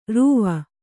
♪ rūva